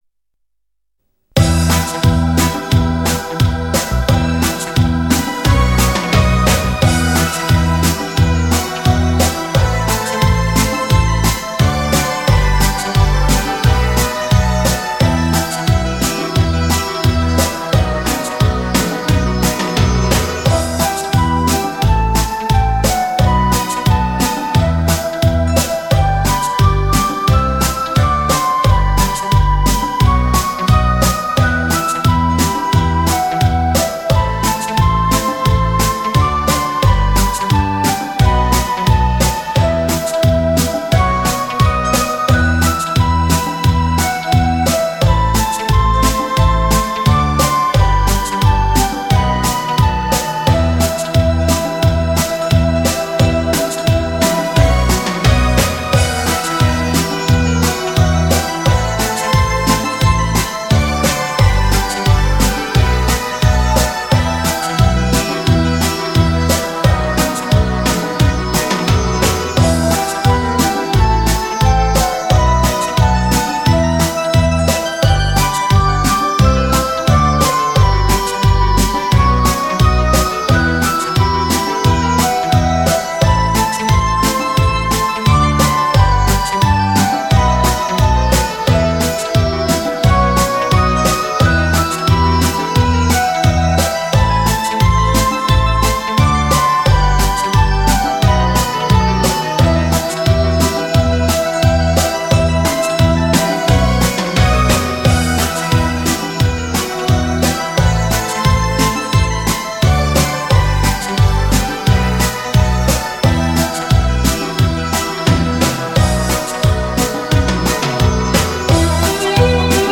专辑格式：DTS-CD-5.1声道
因为有了优美的舞蹈，抒情的音乐，不再显得烦躁与无助……
伦巴